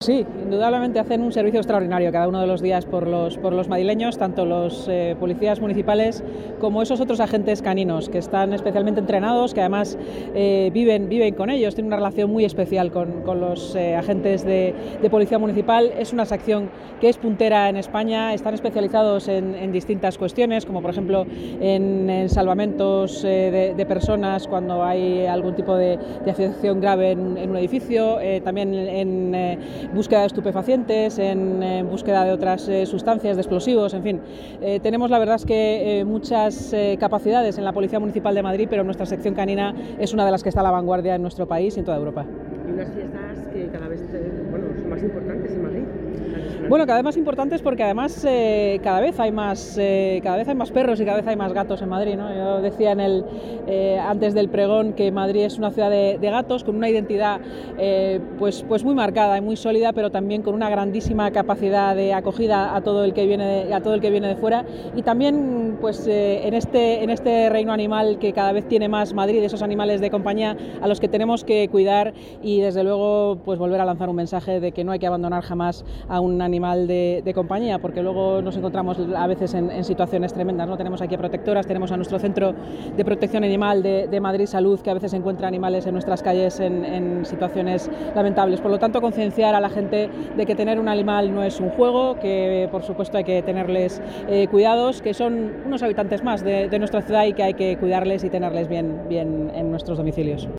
Nueva ventana:Declaraciones de la vicealcaldesa y delegada de Seguridad y Emergencias, Inma Sanz, durante el pregón de San Antón 2024